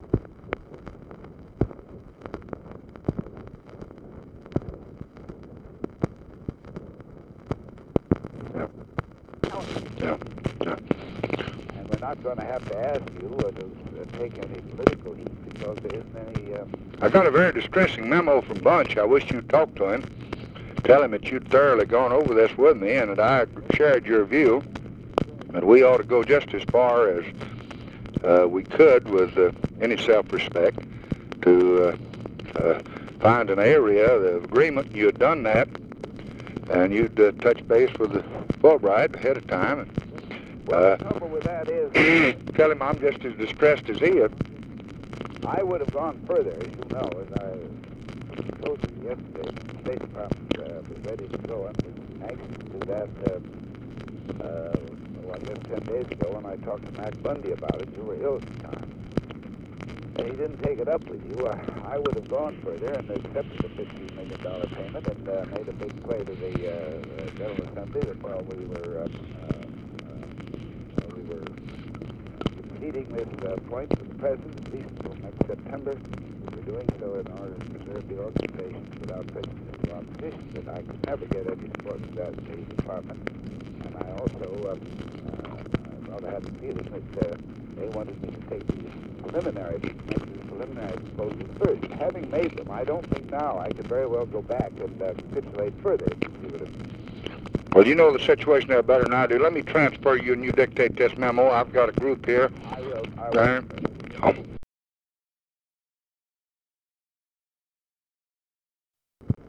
Conversation with (possibly) ADLAI STEVENSON, February 6, 1965
Secret White House Tapes